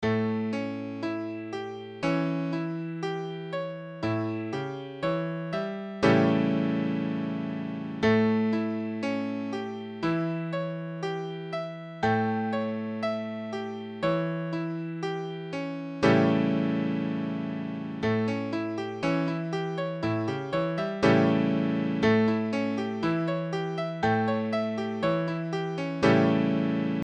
Indication : Accord de La septième